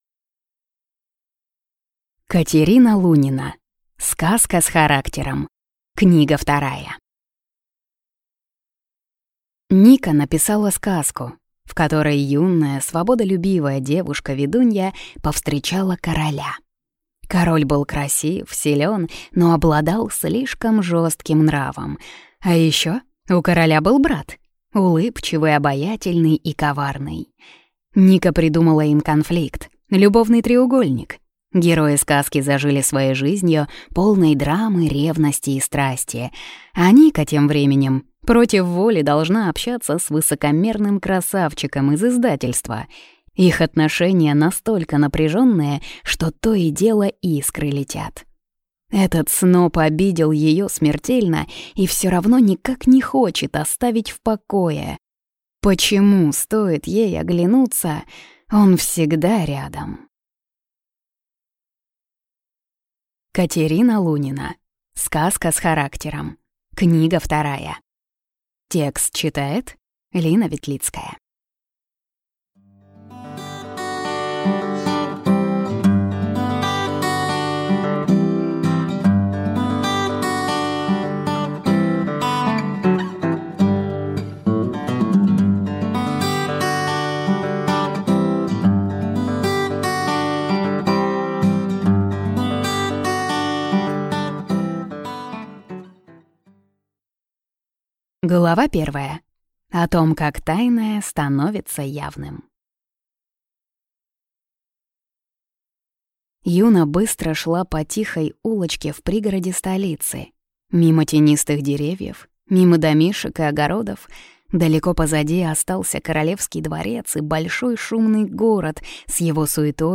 Аудиокнига Сказка с характером. Книга 2 | Библиотека аудиокниг